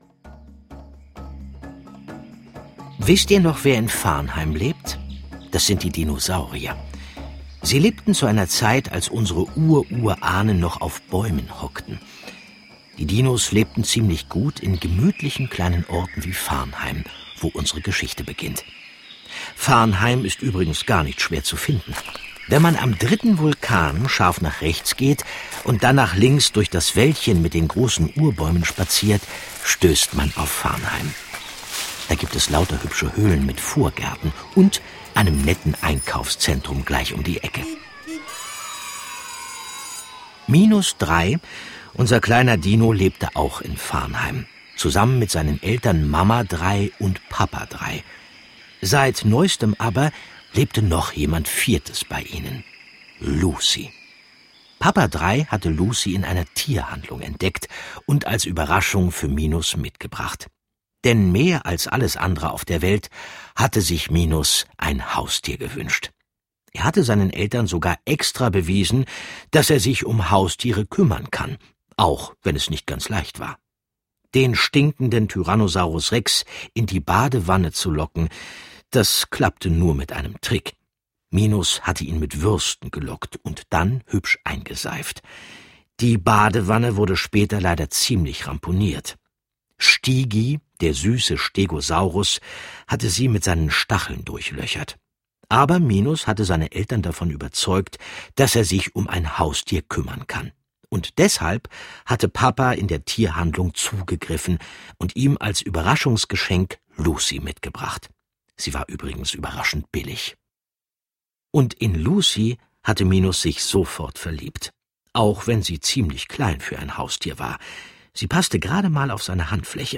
Ravensburger Minus Drei und die laute Lucy ✔ tiptoi® Hörbuch ab 6 Jahren ✔ Jetzt online herunterladen!